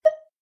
menu-options-hover.ogg